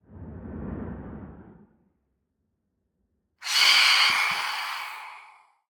Minecraft Version Minecraft Version 1.21.5 Latest Release | Latest Snapshot 1.21.5 / assets / minecraft / sounds / mob / phantom / swoop1.ogg Compare With Compare With Latest Release | Latest Snapshot
swoop1.ogg